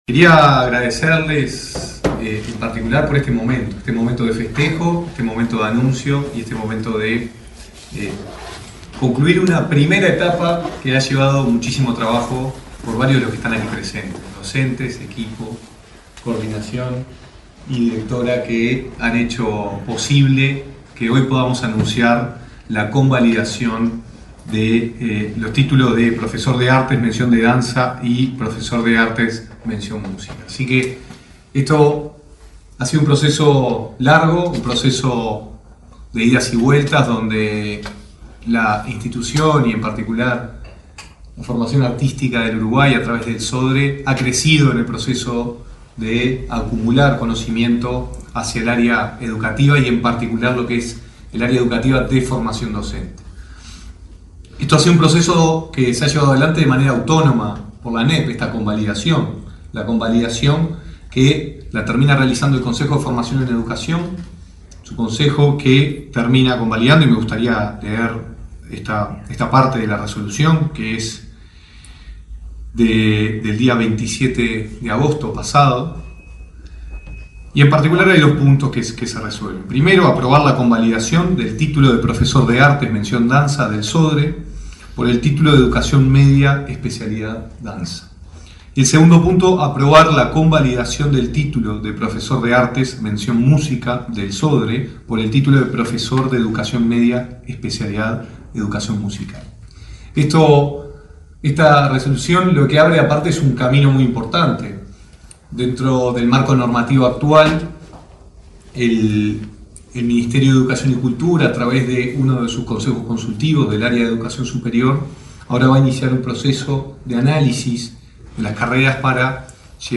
Palabras de autoridades del MEC 04/09/2024 Compartir Facebook X Copiar enlace WhatsApp LinkedIn El director nacional de Cultura, Gonzalo Baroni, y el titular del Ministerio de Educación y Cultura (MEC), Pablo da Silveira, realizaron, este jueves 4 en Montevideo, una conferencia de prensa para informar acerca de la convalidación de la Administración Nacional de Educación Pública de los títulos de las carreras de Formación Docente en Artes. También se brindaron detalles sobre la próxima inauguración del edificio de las Escuelas del Sodre.